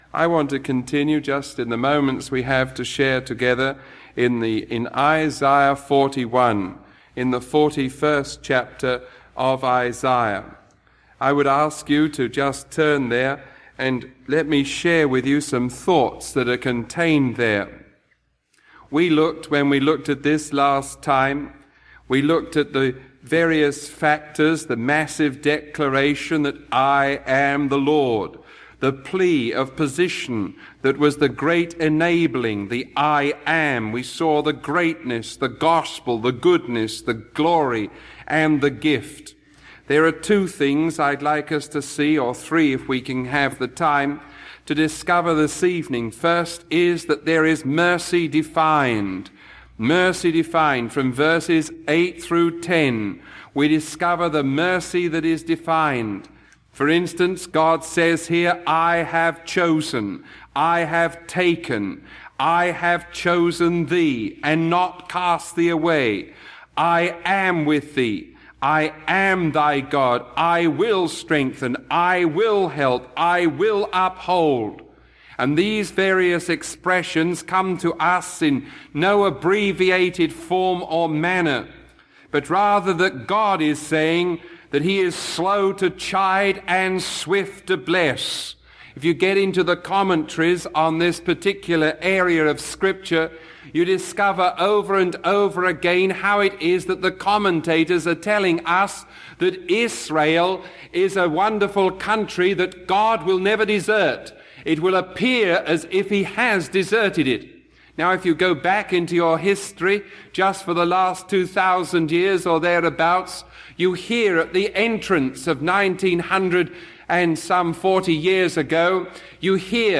Sermon 0073B recorded on July 15